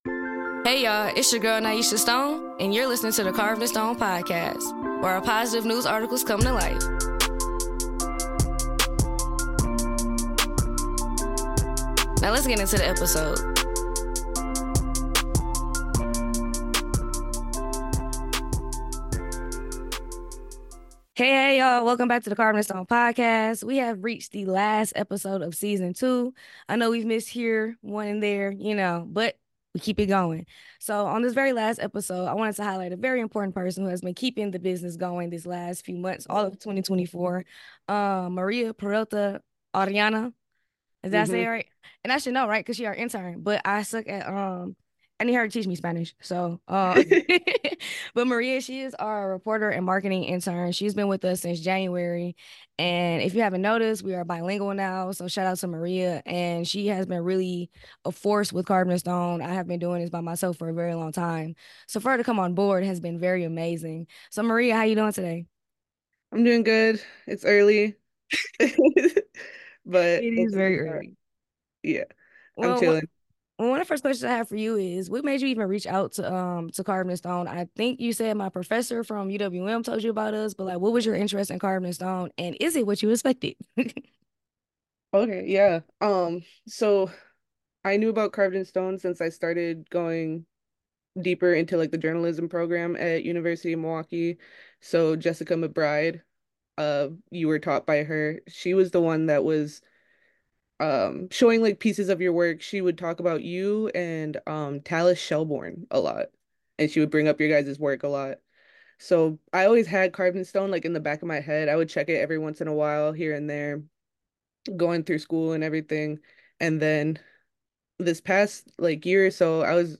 Listen every Tuesday as we interview entrepreneurs, community members, CEOs, and everyday people about the positive things in their lives.